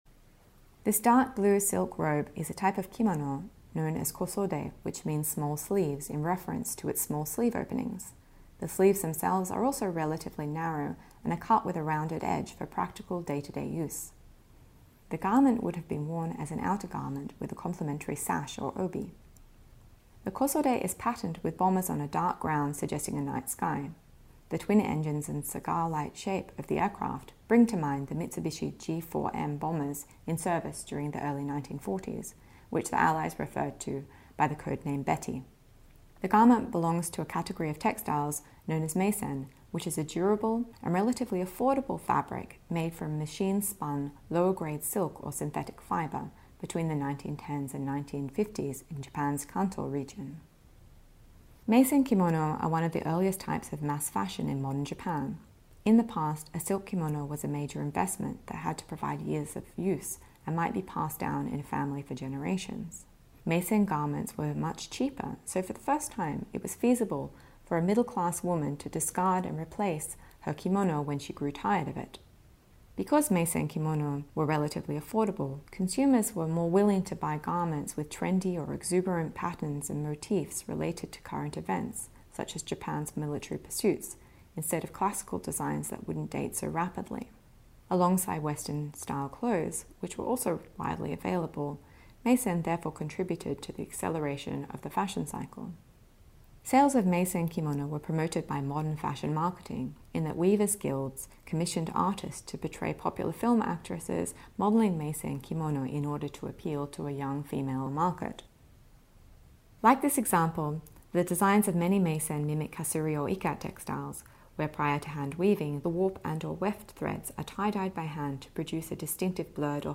This audio guide features an introduction in both English and Chinese, and expert commentary on 8 works of art from the exhibition.